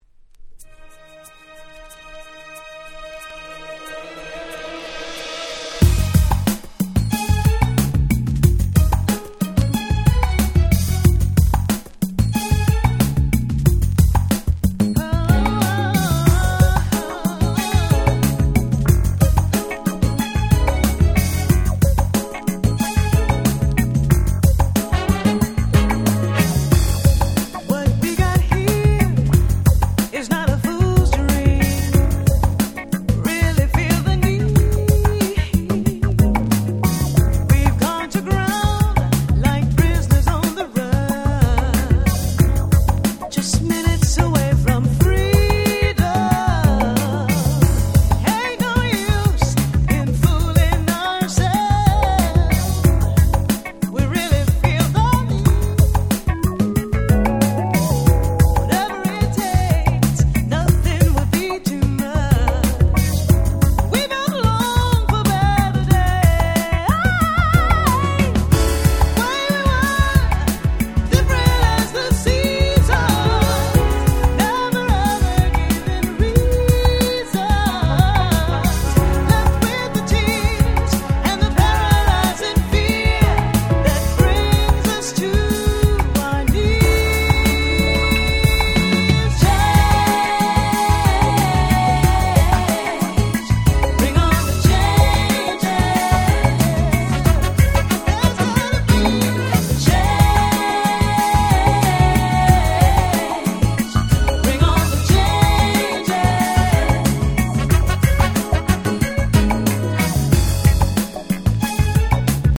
92' Very Nice Acid Jazz / R&B / UK Soul !!
ゆったりとしたBeatの名曲